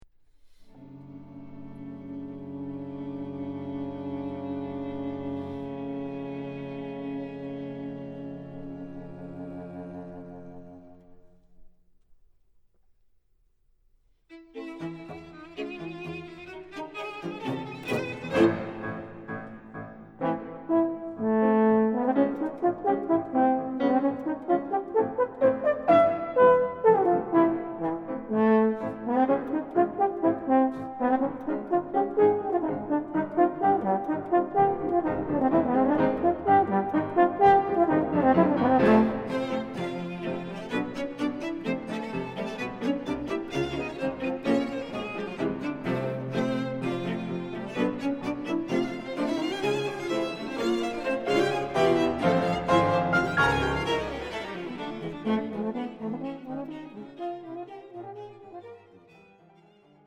Quintet for French Horn, Violin, Viola, Cello and Piano